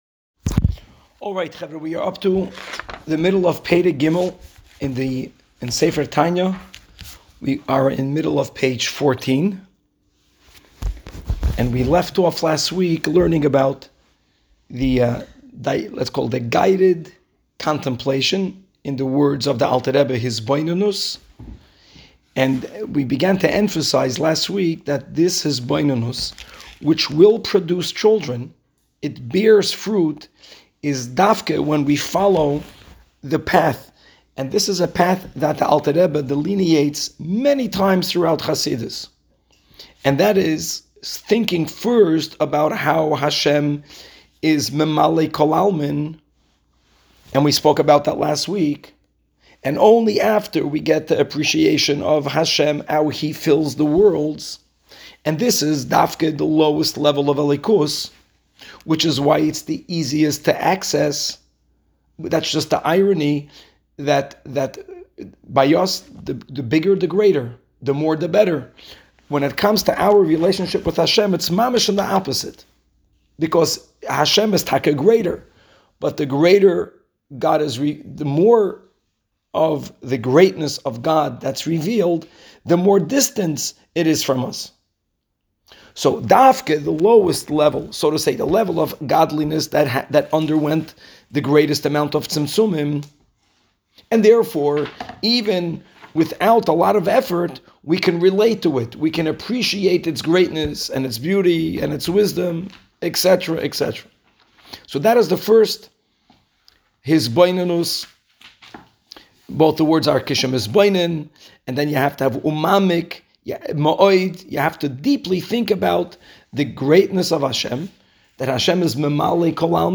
Woman's Class